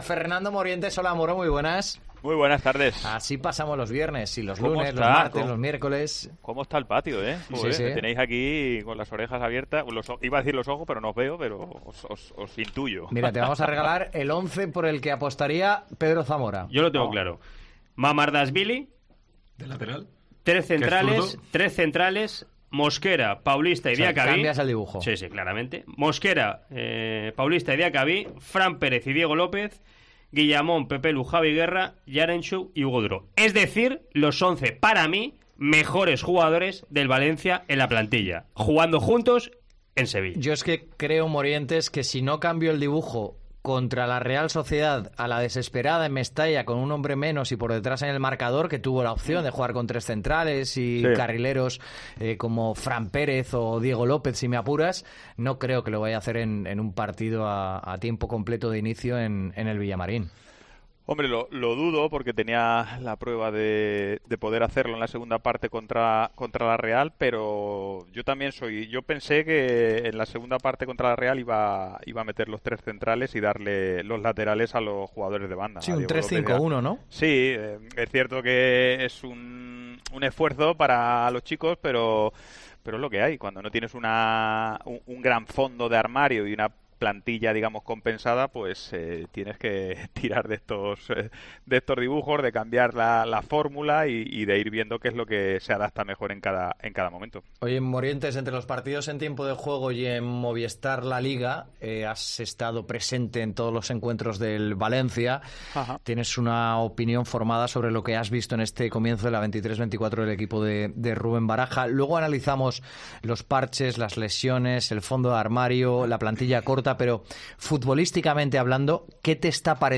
Fernando Morientes , leyenda del futbol y comentarista COPE, analiza en Deportes COPE Valencia la actualidad del Valencia CF, en el que jugó entre 2006 y 2009.